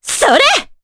Veronica-Vox_Attack3_jp.wav